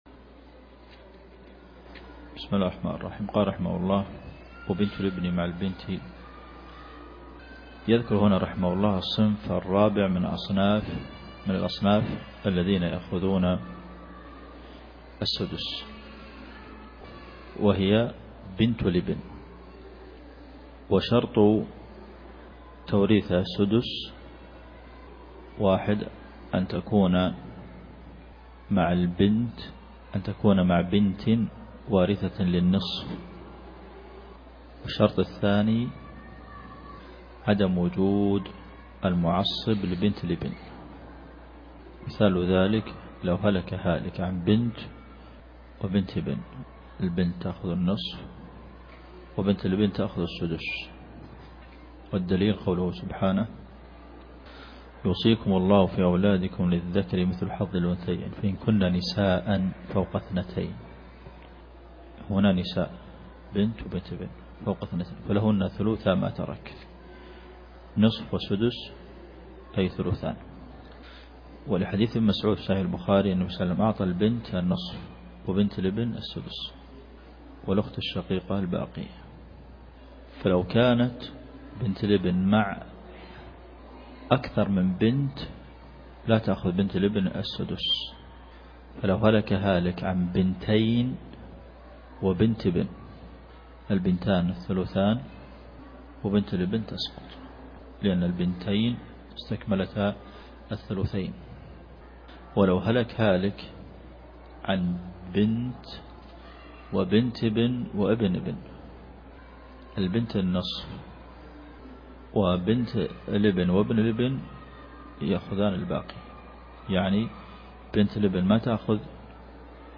عنوان المادة 15 دورة في علم الفرائض من قوله (وَبِنْتُ الِابْـنِ)، إلى قوله (يَا أُخَيَّ أَدْلَتِ)